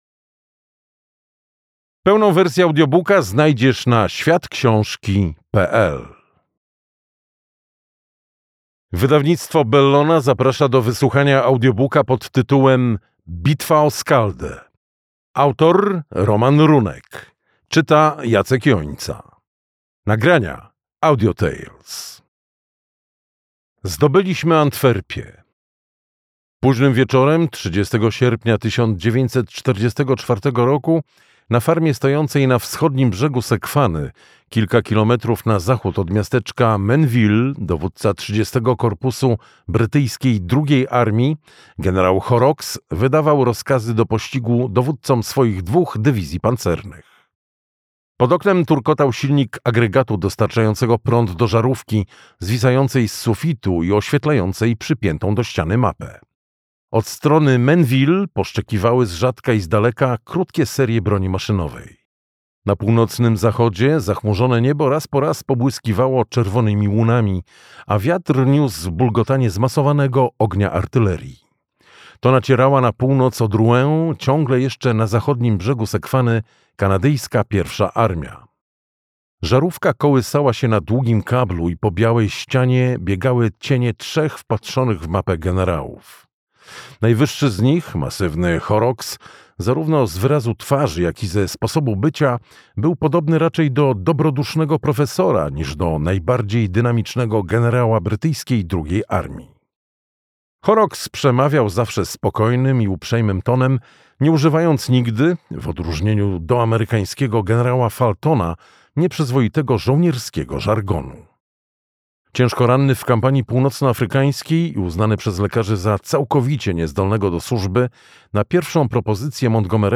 Bitwa o Skaldę - Roman Runek - audiobook